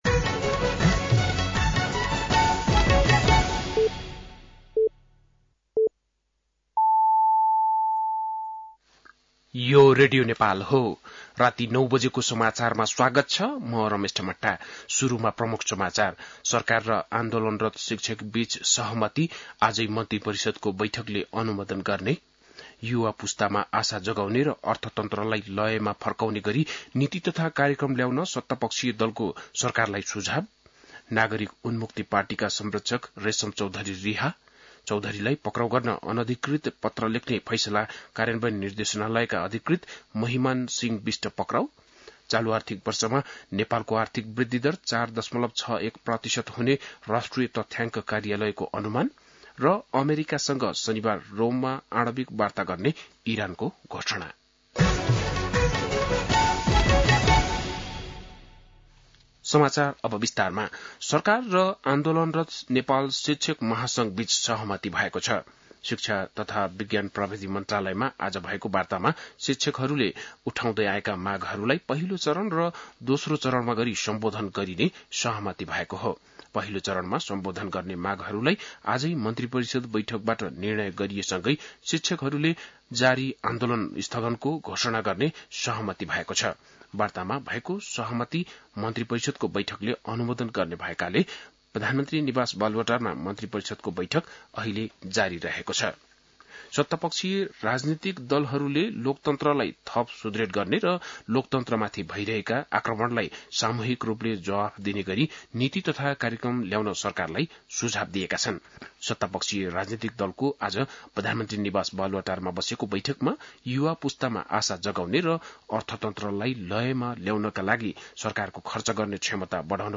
बेलुकी ९ बजेको नेपाली समाचार : १७ वैशाख , २०८२